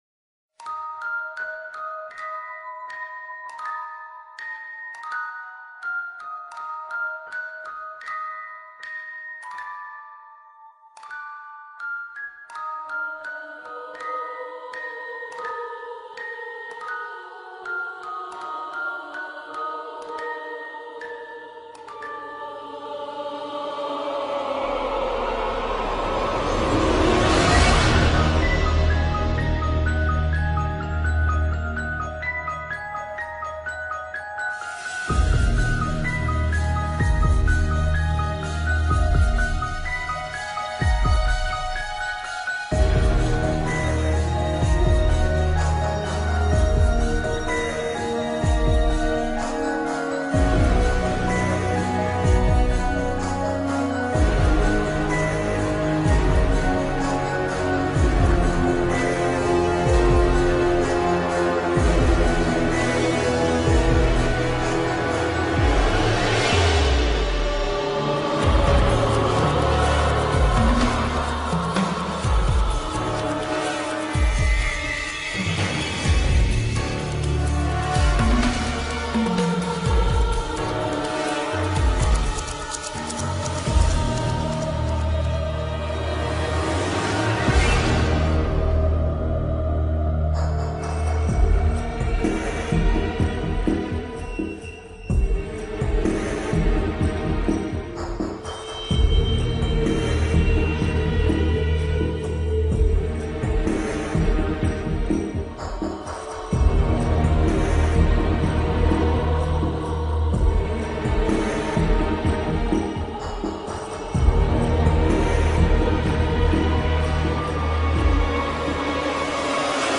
musique qui fait peur.mp3